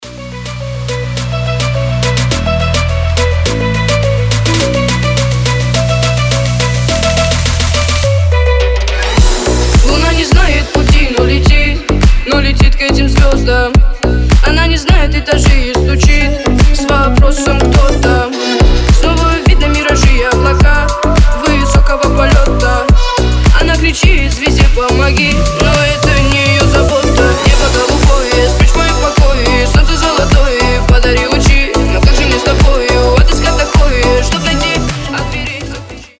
• Качество: 320, Stereo
громкие
женский вокал
remix
восточные мотивы
Club House
Флейта